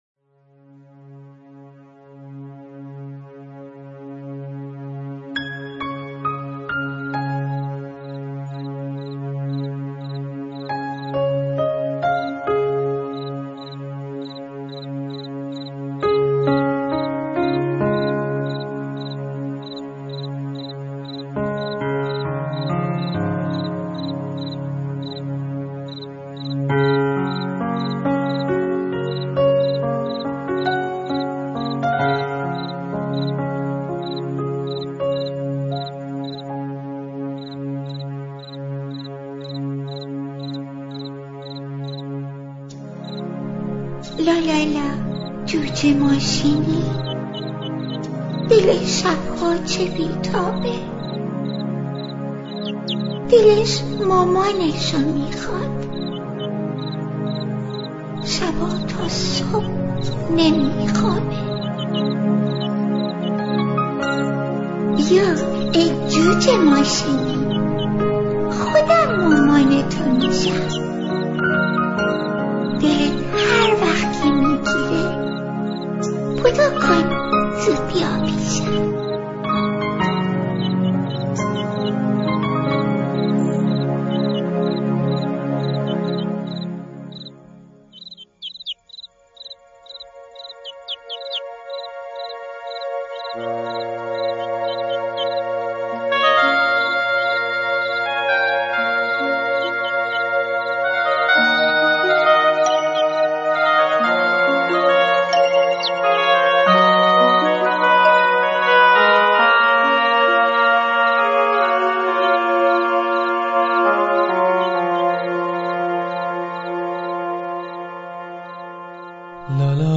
آهنگ لالایی